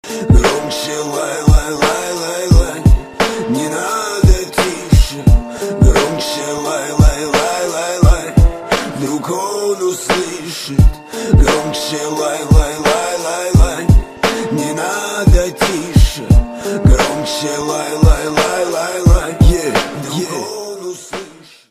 лирика
Хип-хоп
грустные
русский рэп